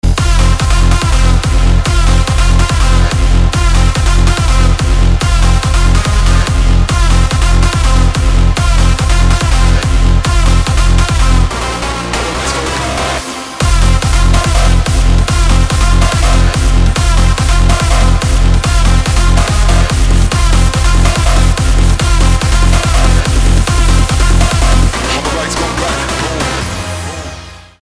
• Качество: 320, Stereo
красивые
dance
club
electro
бешеный ритм